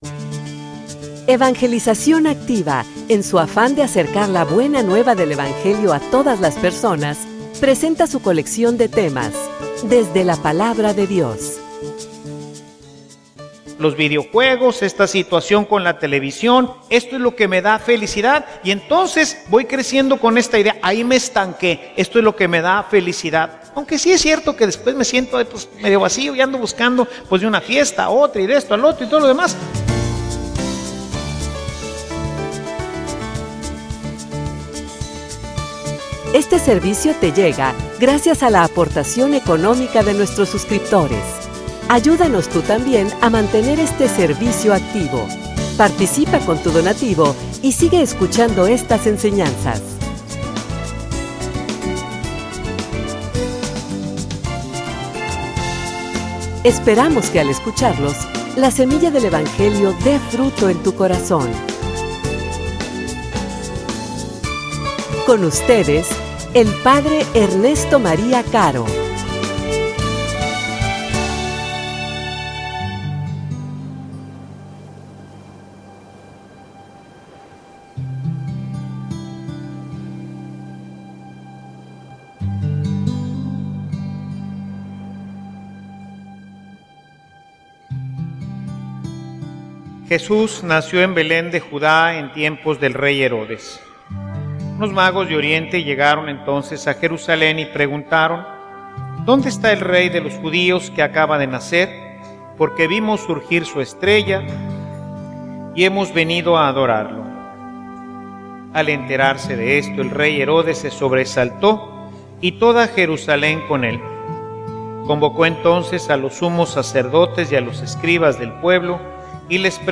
homilia_Desinstalate_y_busca.mp3